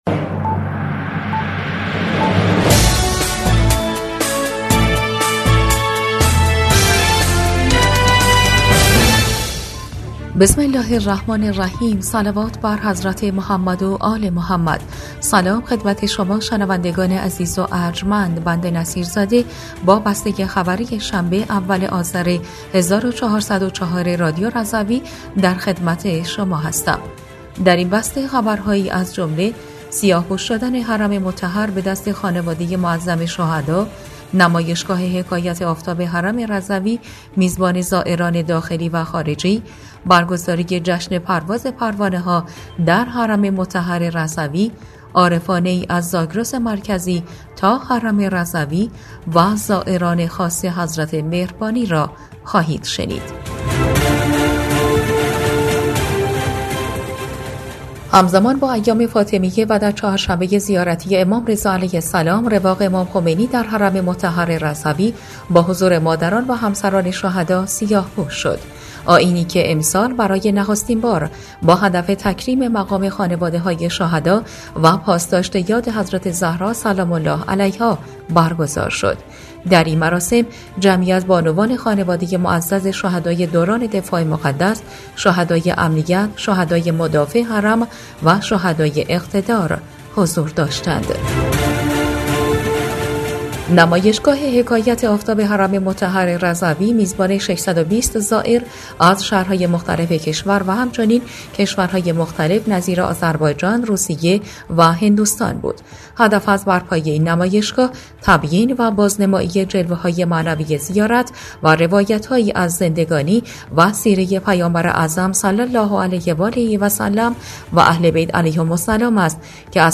بسته خبری ۱ آذر ۱۴۰۴ رادیو رضوی؛